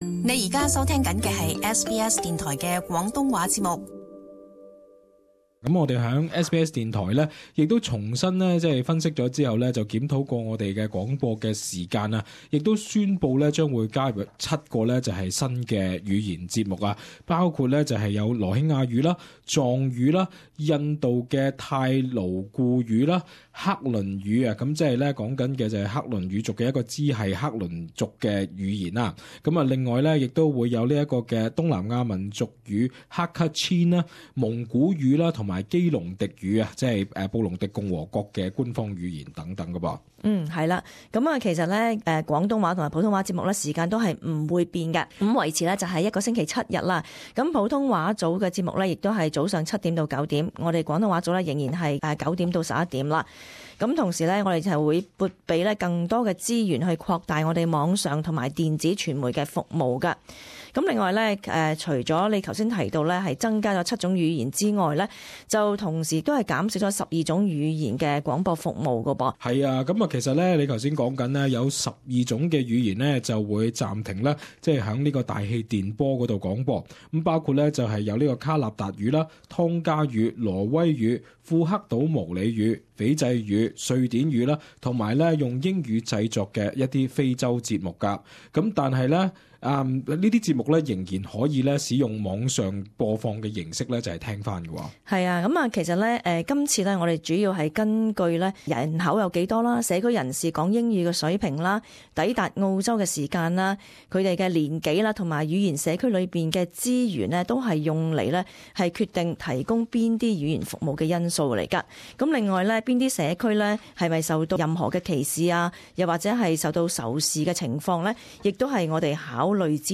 【時事報導】SBS電台服務大改革